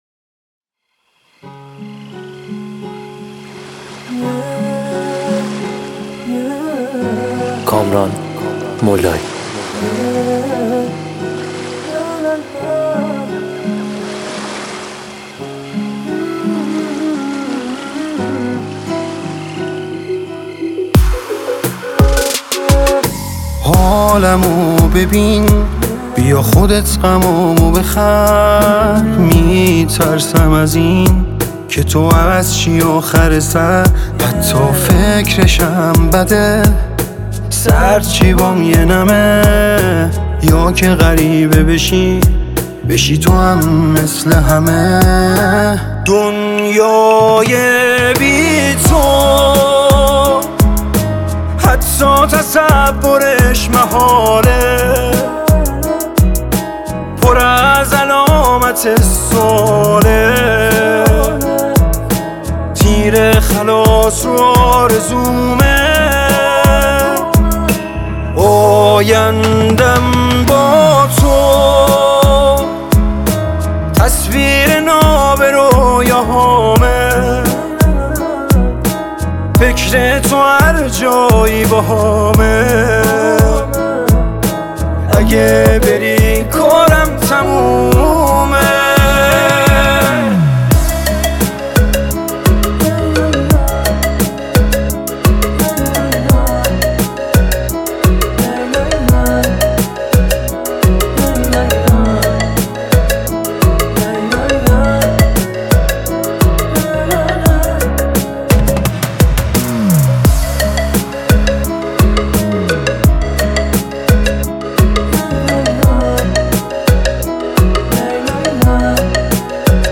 پاپ
اهنگ ایرانی